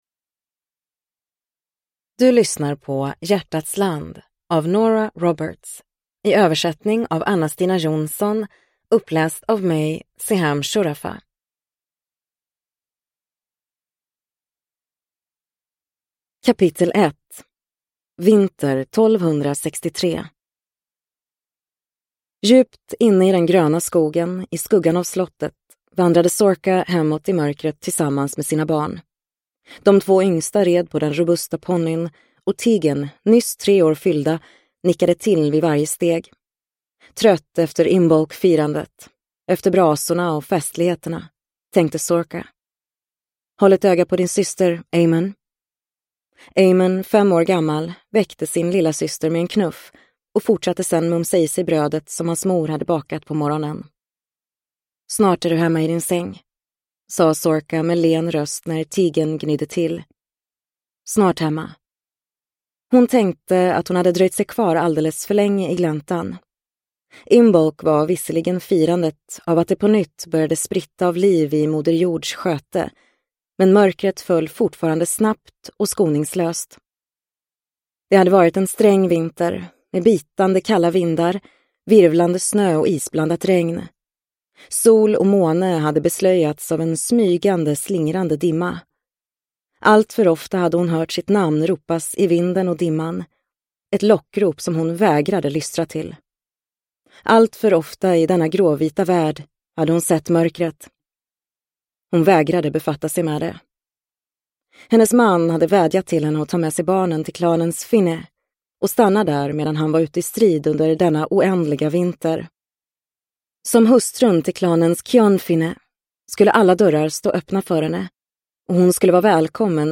Hjärtats land – Ljudbok – Laddas ner